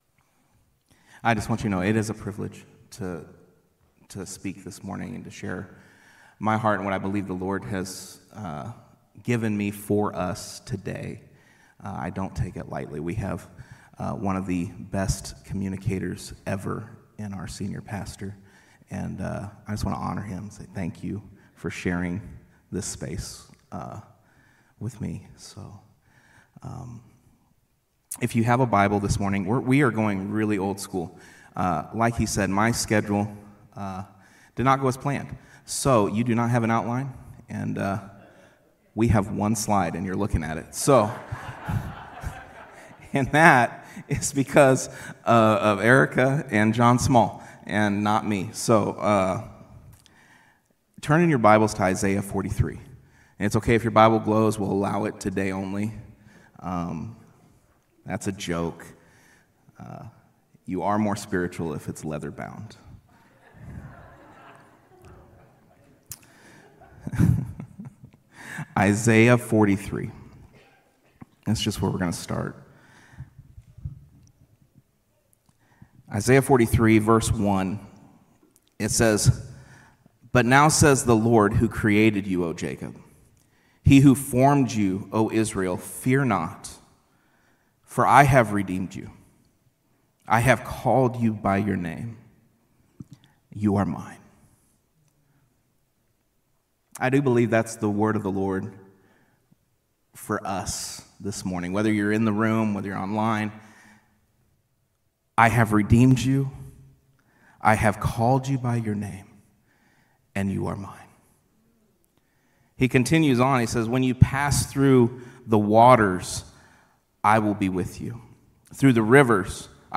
Psalm 138:8 Service Type: Sunday Morning Sermon